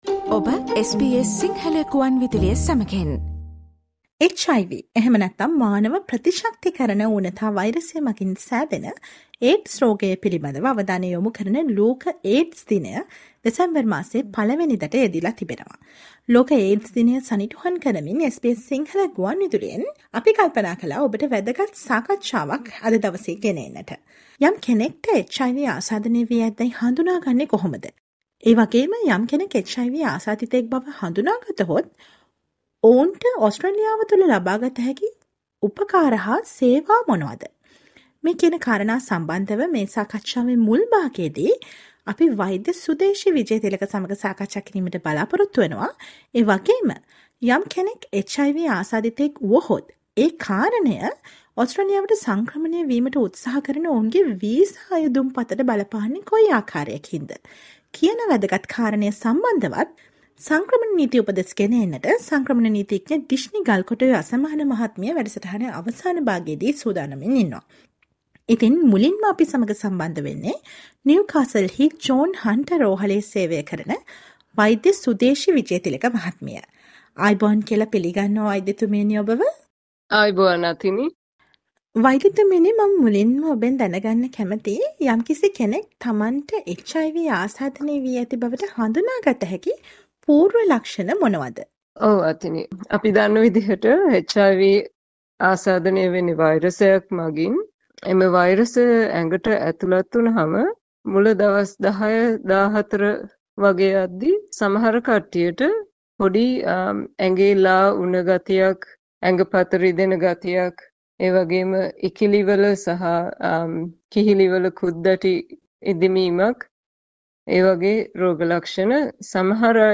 Listen to the SBS Sinhala interview